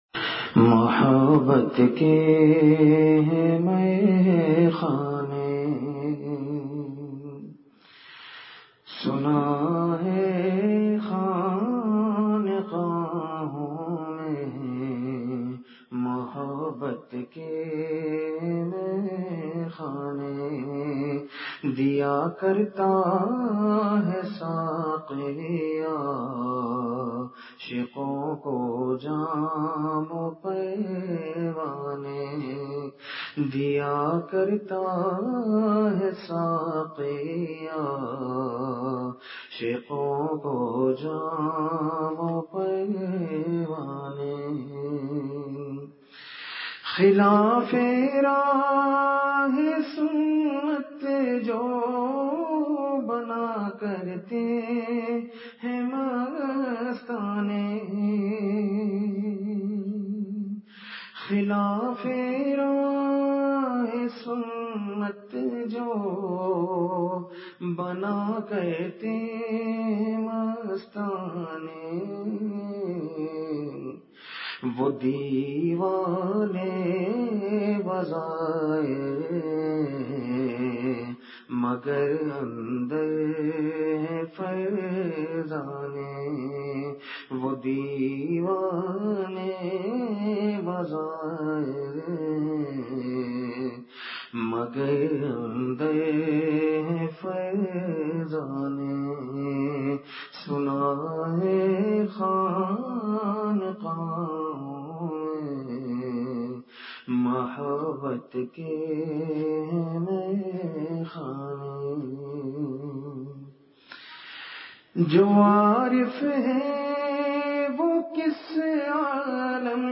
Please download the file: audio/mpeg مجلس محفوظ کیجئے اصلاحی مجلس کی جھلکیاں بمقام : دارالعلوم السادات اصحاب بابا پشاور۔
بعد مغرب بیان
بہت ہی گریہ سے درد بھری دعا۔